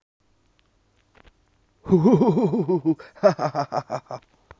lachen1.wav